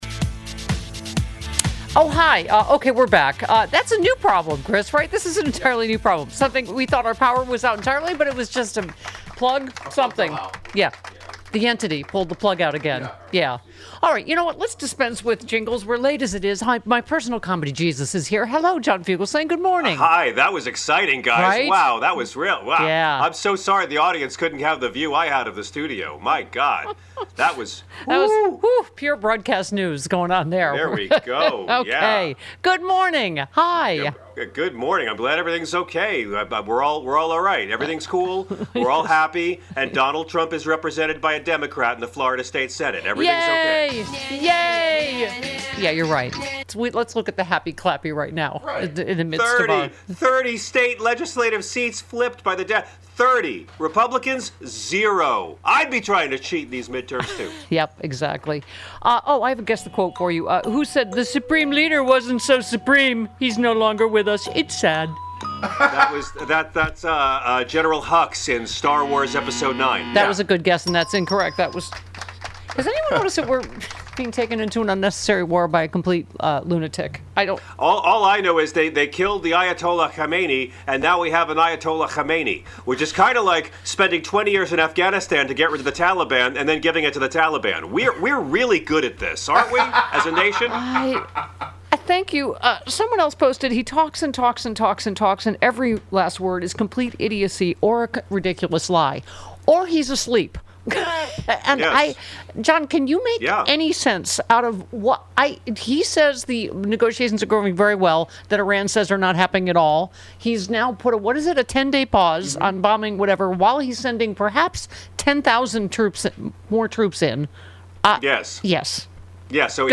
We had a power outage in studio so we lost the first half of the show. But today’s guests are: John Fugelsang & Frangela